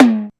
Tom9.aif